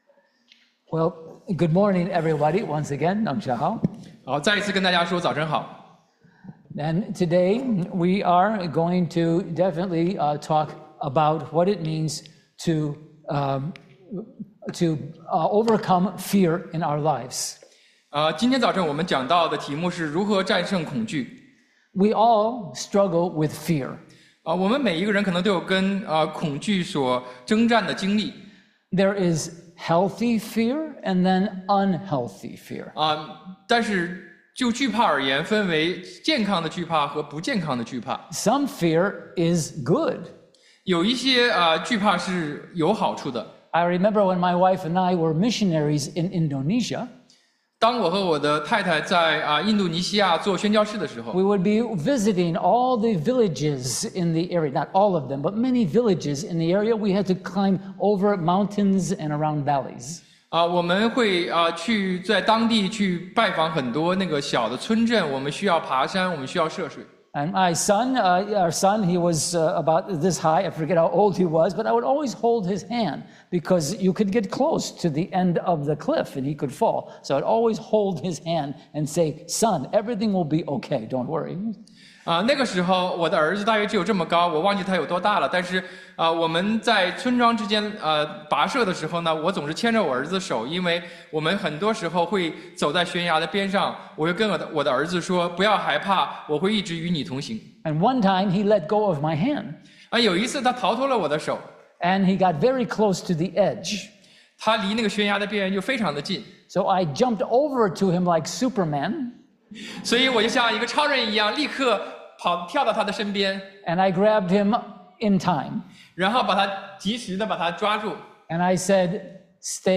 Sermons – Page 3 – Chinese Christian Church of Baltimore